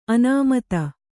♪ anāmata